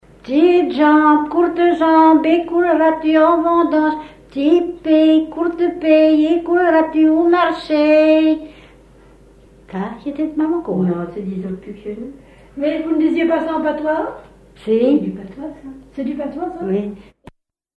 Thème : 0078 - L'enfance - Enfantines - rondes et jeux
Fonction d'après l'analyste formulette enfantine : sauteuse ;
Genre brève
Catégorie Pièce musicale inédite